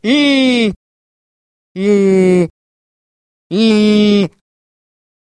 Звуки высовывания языка
Звук, который издает анимационный персонаж, показывая язык